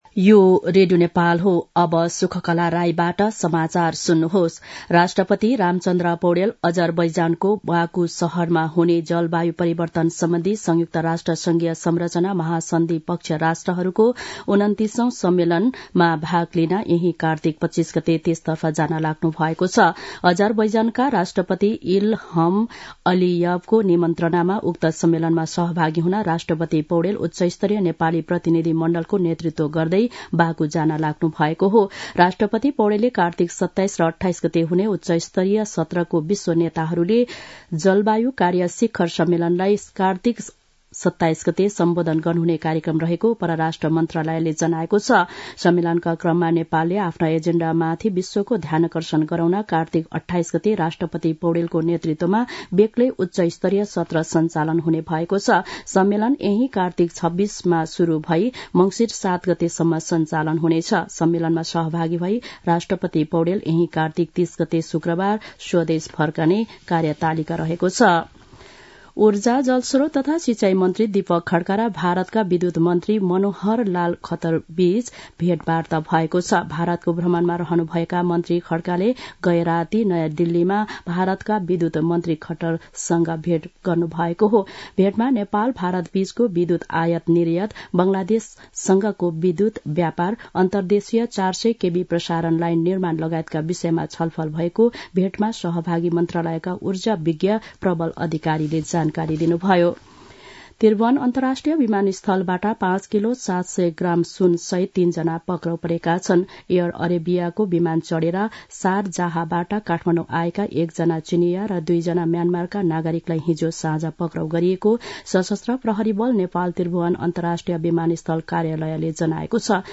दिउँसो १ बजेको नेपाली समाचार : २२ कार्तिक , २०८१